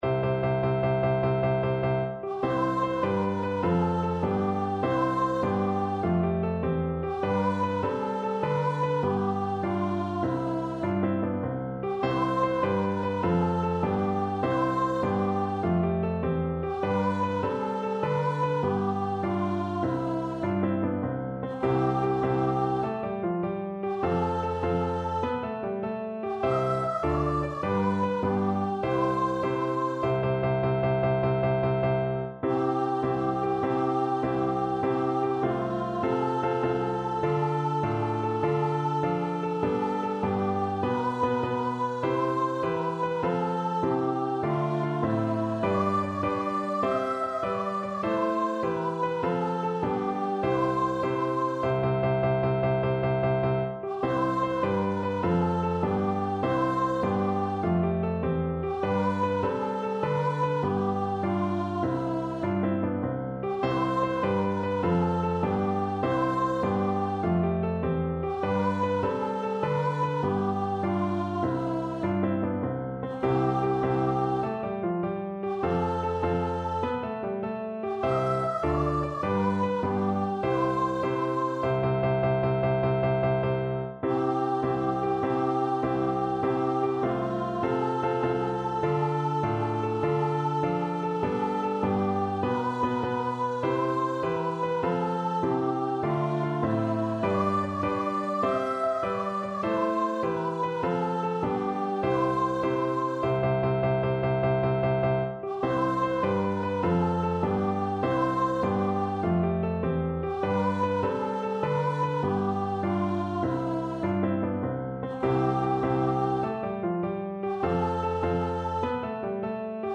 Free Sheet music for Voice
Voice
C major (Sounding Pitch) (View more C major Music for Voice )
6/8 (View more 6/8 Music)
~ = 100 Moderato .=100
C5-E6
Traditional (View more Traditional Voice Music)
kuwait_national_anthem_VOICE.mp3